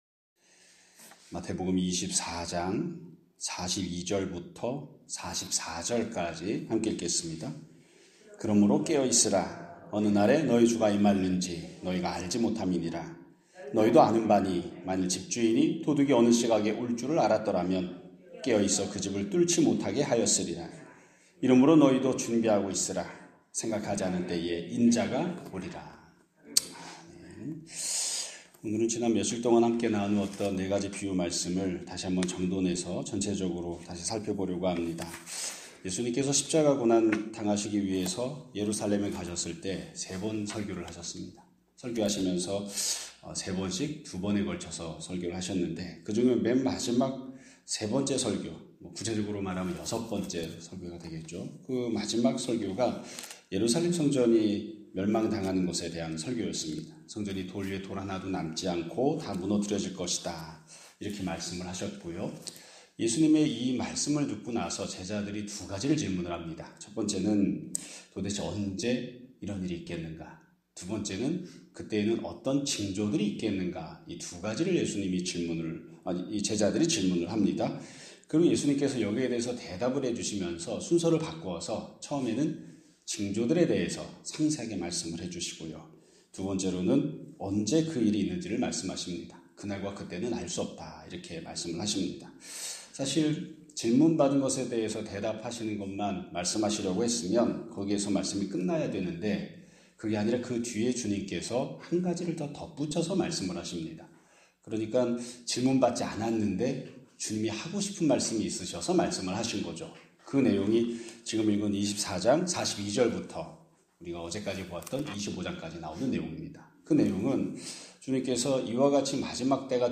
2026년 3월 25일 (수요일) <아침예배> 설교입니다.